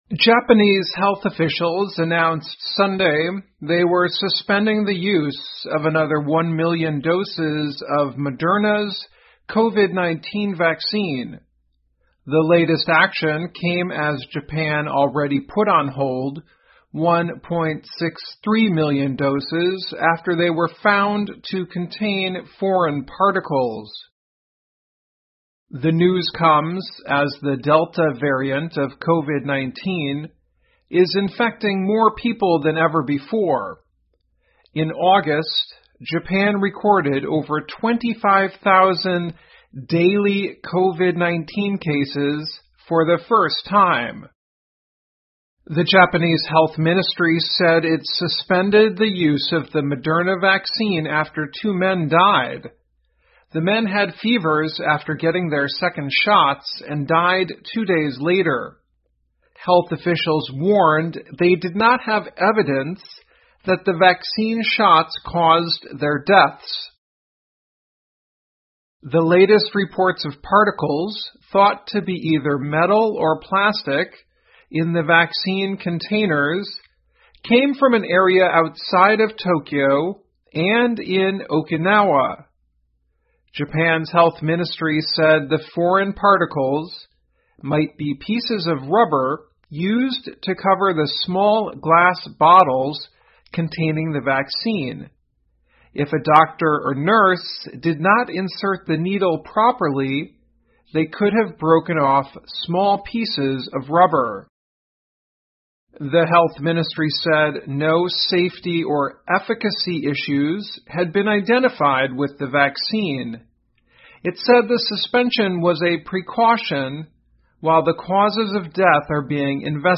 VOA慢速英语2021 --日本暂停使用部分莫德纳疫苗供应 听力文件下载—在线英语听力室